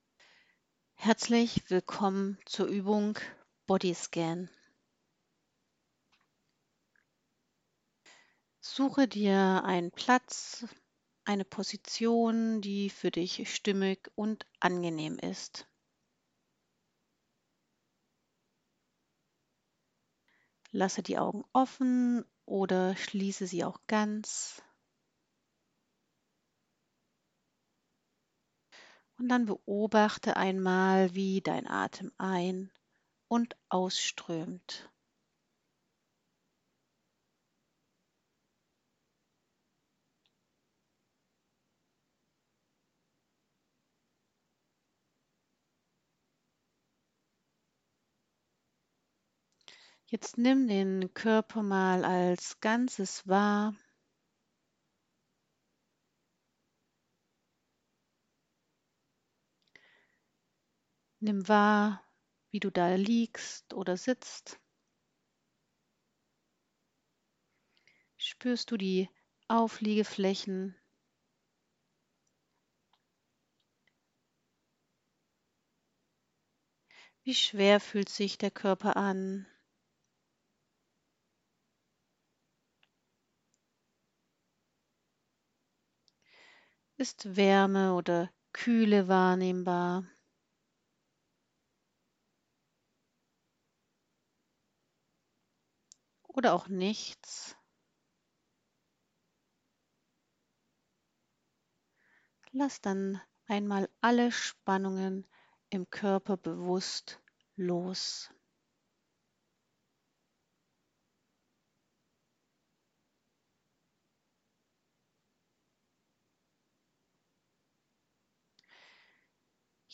4. Der Bodyscann (Audio-Session)
Bodyscann.mp3